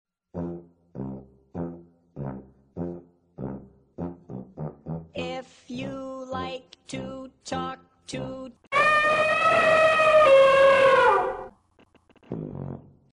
contribs)forgive me (dw it's not that loud)